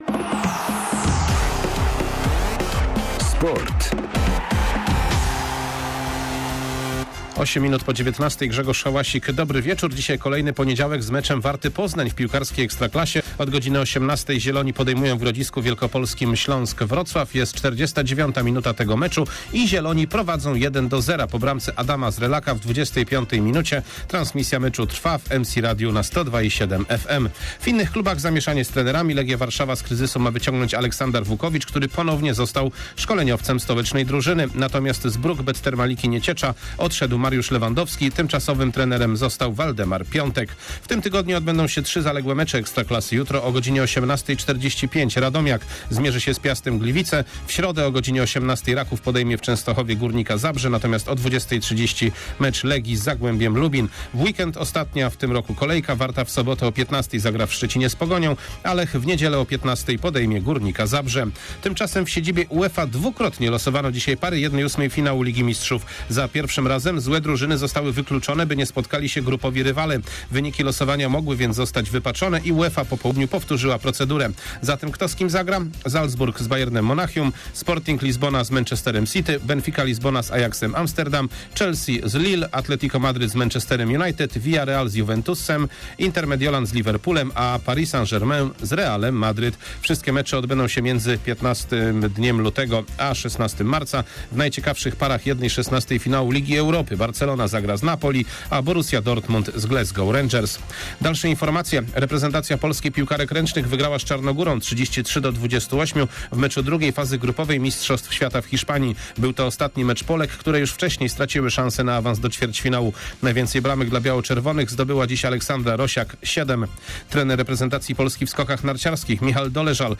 13.12.2021 SERWIS SPORTOWY GODZ. 19:05